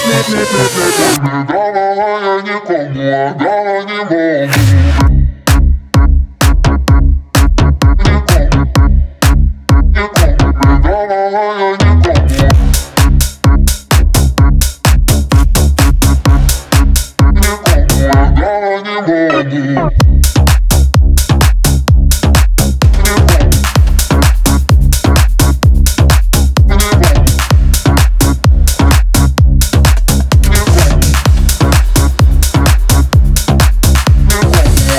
Жанр: Электроника / Украинские